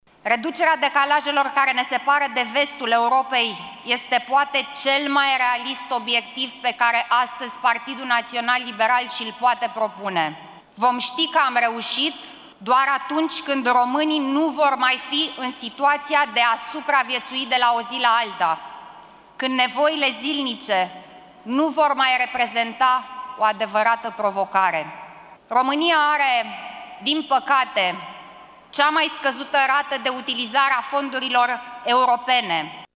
Iată declaraţiile Alinei Gorghiu la debutul şedinţei:
gorghiu.mp3